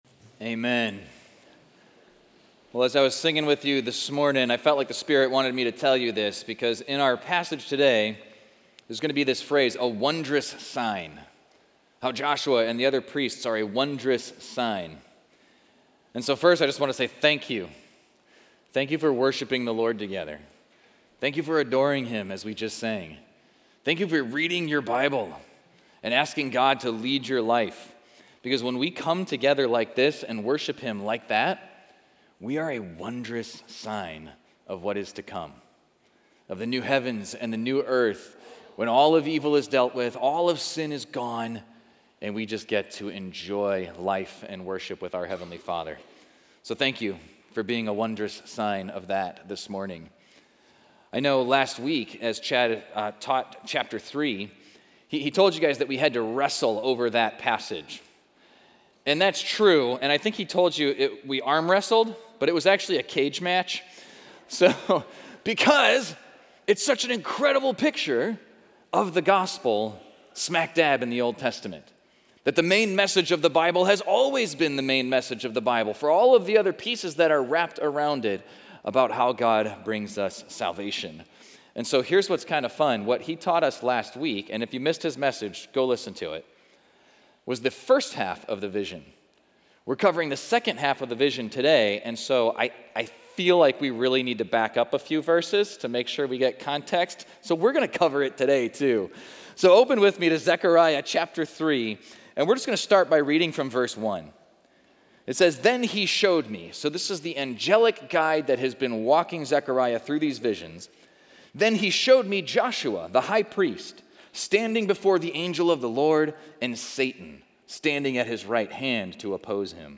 Equipping Service / Zechariah: Hope-Ray Vision / How to Recognize God's Branch